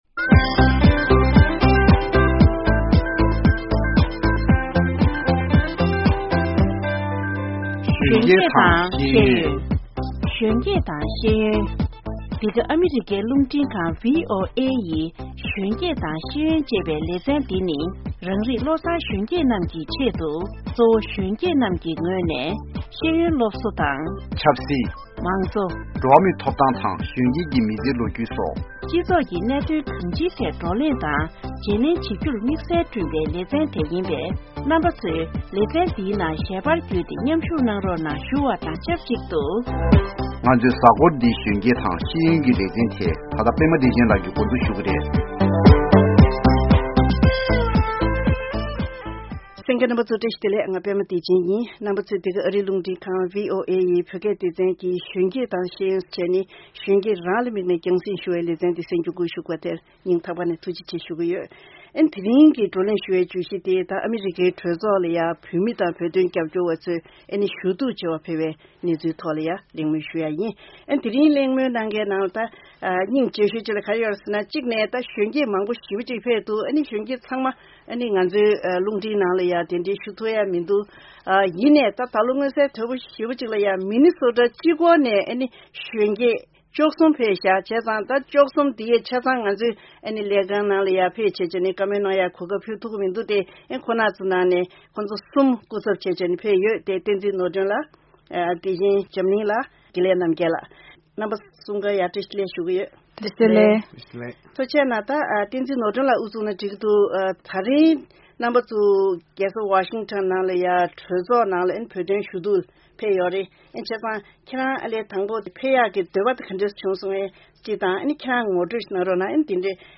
༄༅།། ང་ཚོའི་གཟའ་འཁོར་འདིའི་གཞོན་སྐྱེས་དང་ཤེས་ཡོན་ལས་རིམ་ནང་དུ་༢༠༡༩་ལོའི་ཨ་རིའི་གྲོས་ཚོགས་ལ་བོད་དོན་ཞུ་གཏུགས་ལས་འགུལ་སྐབས་སུ་་མི་ནི་སོ་ཌ་མངའ་སྡེ་གཅིག་པུ་ནས་བོད་པ་ན་གཞོན་བཅུ་ལྷག་ཕེབས་ཡོད་ཅིང་། ཁོང་ཚོའི་གྲས་ཀྱི་ན་གཞོན་གསུམ་ལ་ང་ཚོའི་རླུང་འཕྲིན་ཁང་གིས་བཅར་འདྲི་ཞུས་པ་དེ་གསན་རོགས་གནང་།།